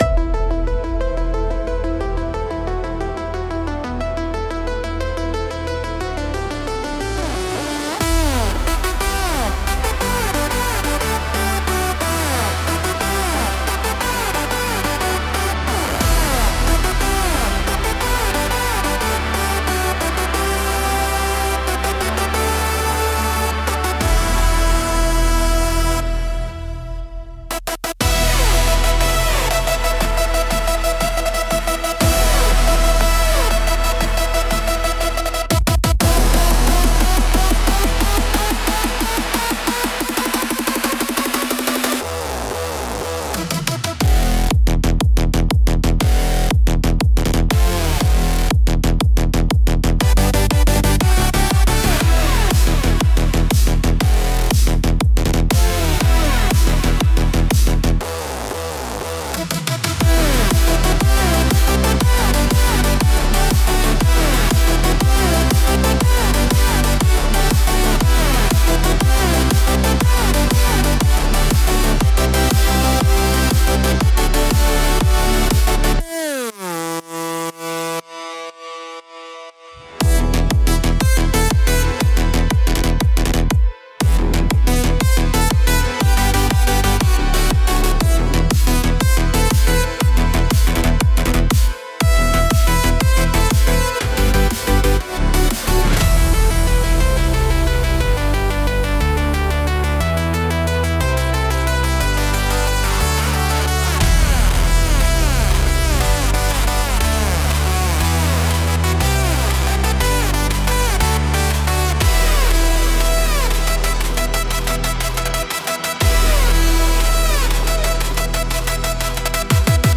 המיקס נשמע טוב.